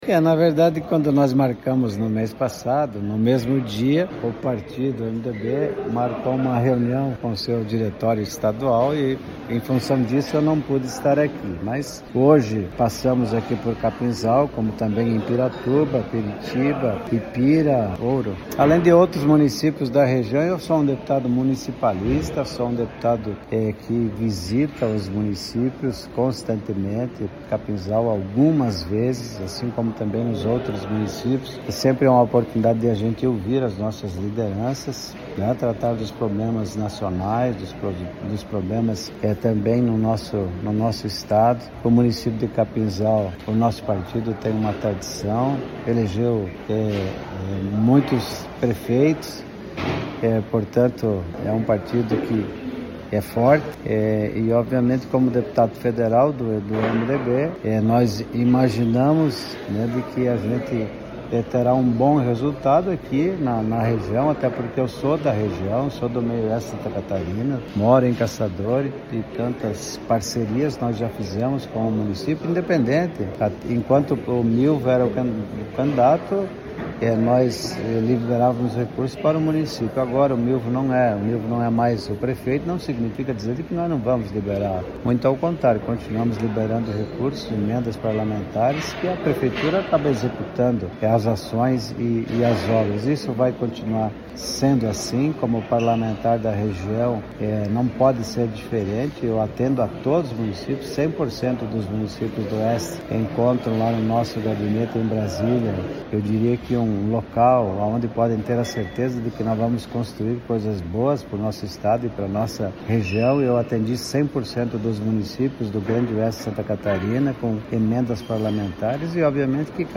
O MDB de Capinzal recebeu, em Capinzal, na sexta-feira, 20 de março, o deputado federal Valdir Cobalchini, que se reuniu com os peemedebistas no mais tradicional restaurante local da Família Riquetti, prestes a completar 50 anos em 2027, fortalecendo o diálogo e as parcerias regionais.